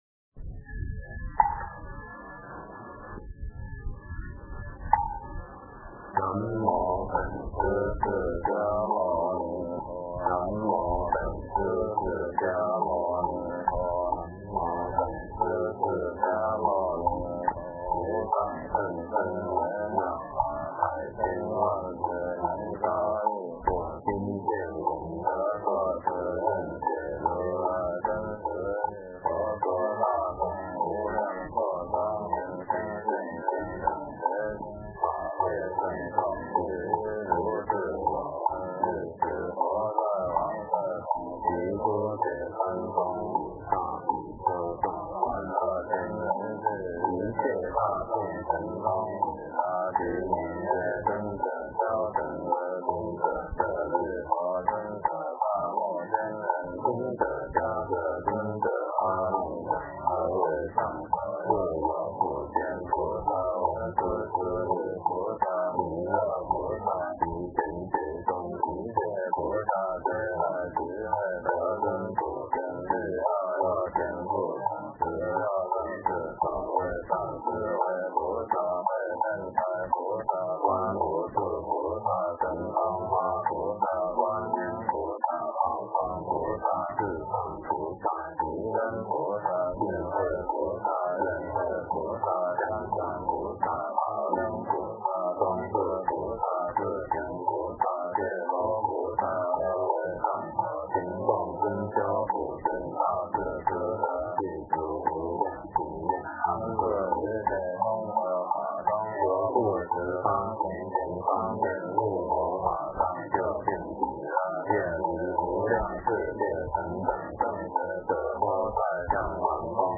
佛说无量寿经（念诵）